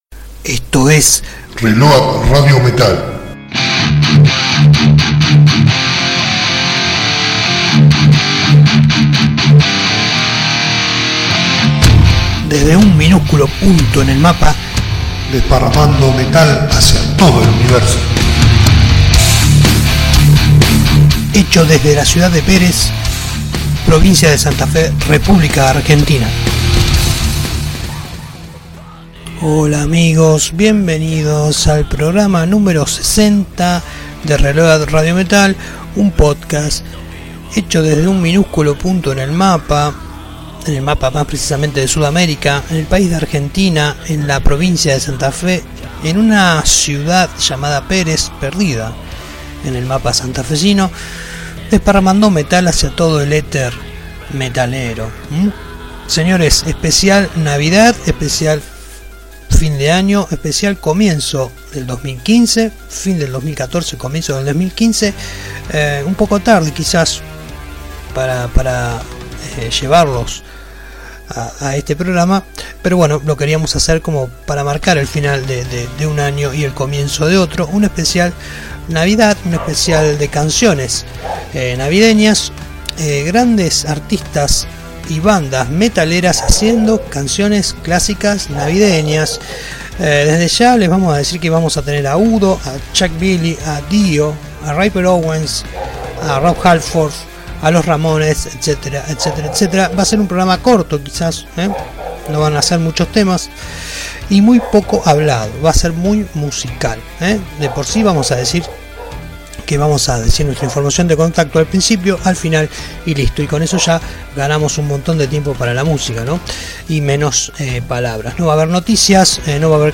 versiones más "metálicas" de las canciones más famosas